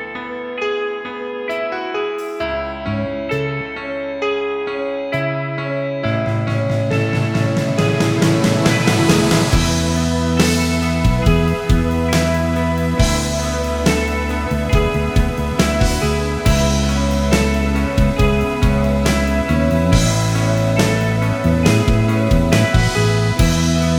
Minus Main Guitar Soft Rock 3:48 Buy £1.50